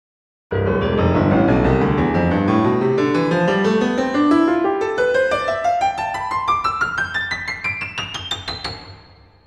وسعت صدا
Pitch_Music.wav.mp3